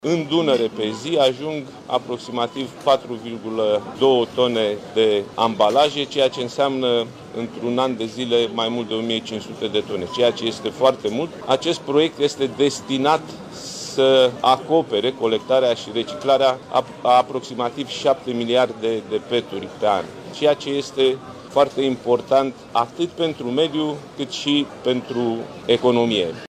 Prezent la conferința privind lansarea sistemului de garanție returnare, președintele PNL, Nicolae Ciucă a vorbit despre cantitatea uriașă de deșeuri din România care ajunge de multe ori în apa Dunării: